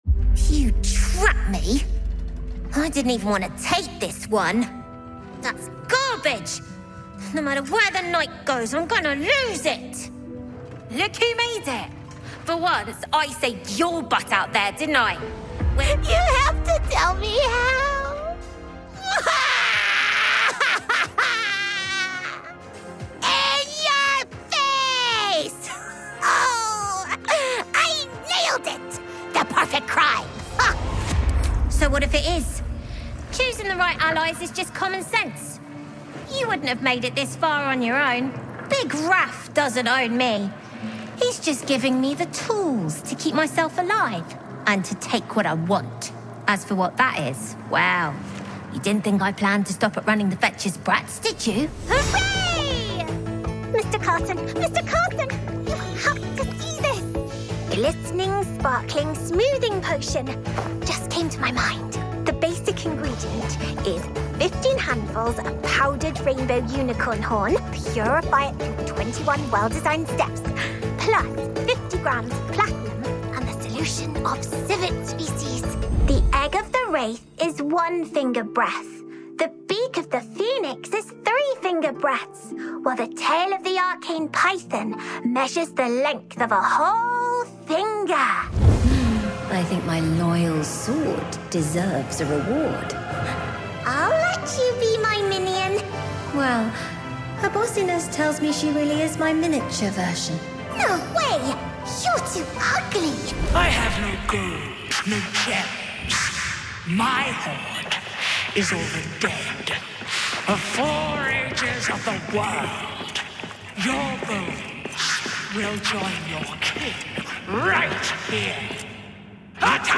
Video Game Showreel
Female
Bright
Friendly
Playful